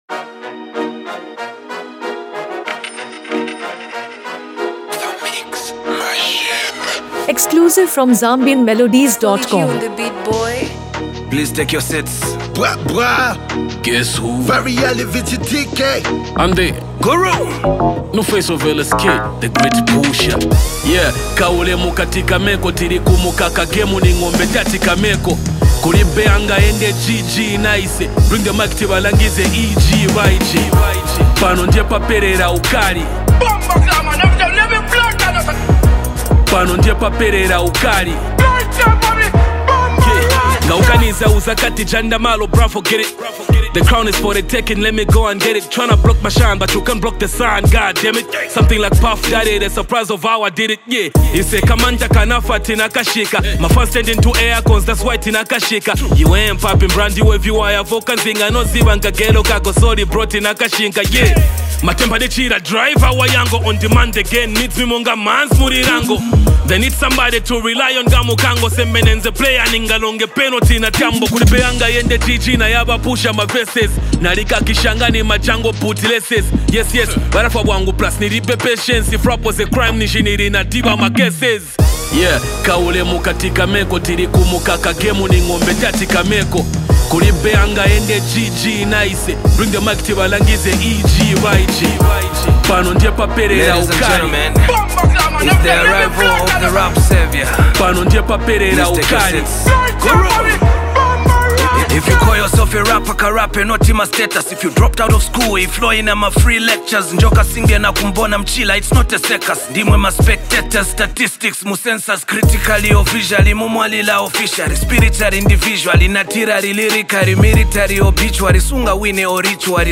Zambian Music